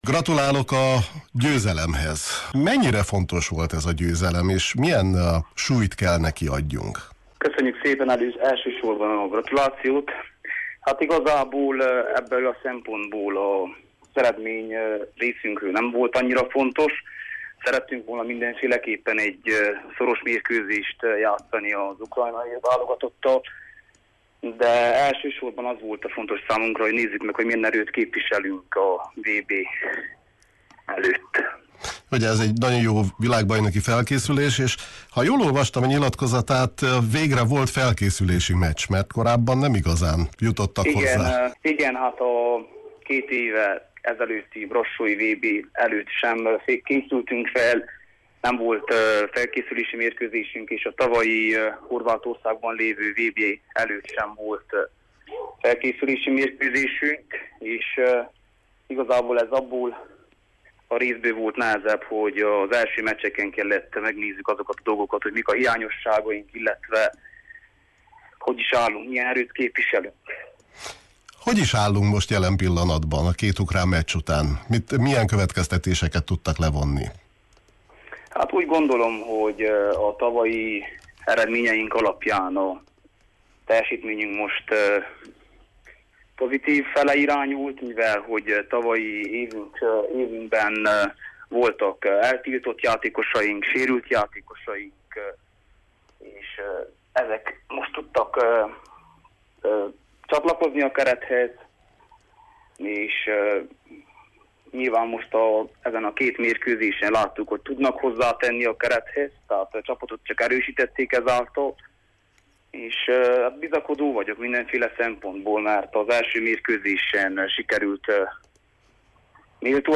beszélgetett a Kispad sportműsorunkban: